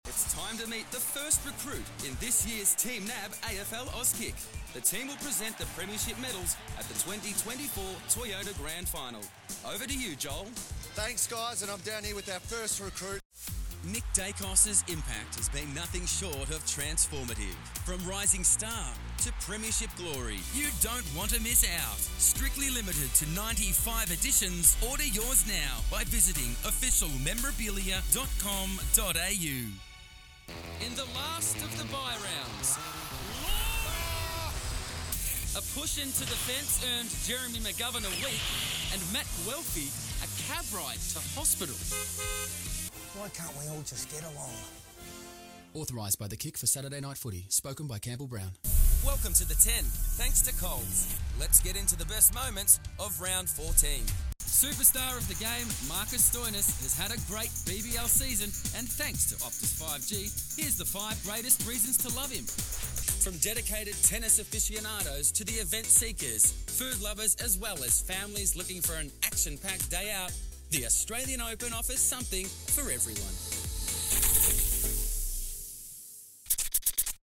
Listen here to sample iconic and emerging voices for commercial, corporate, narration , animation and character voice projects.
Male, 30's    Melbourne